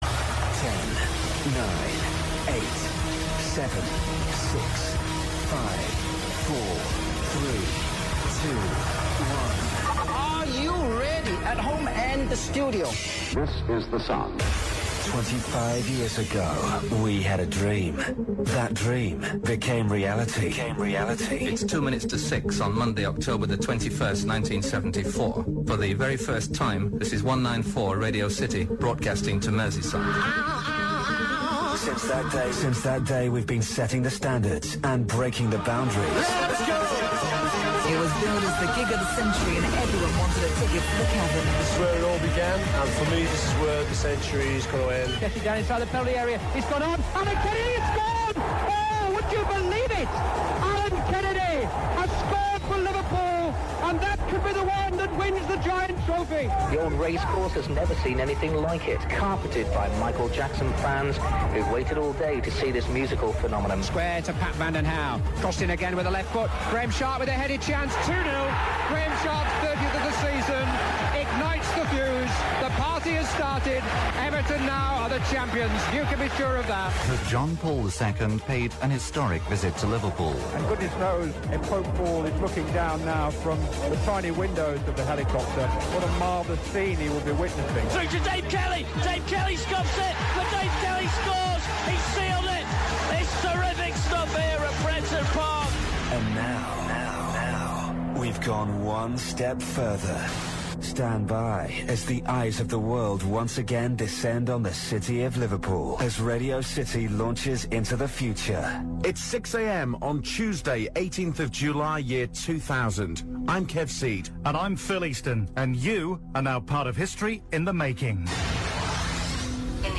Radio City's first broadcast from the top of the tower
18 years ago, Radio City moved to the top of St. Johns Beacon. Here the historic first brodacast from the top of the Radio City Tower.